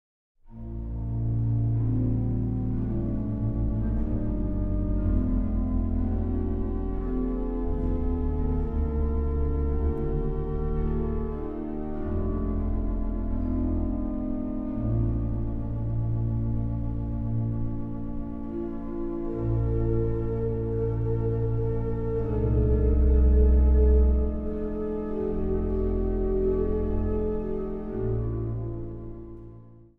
Meditatief orgelspel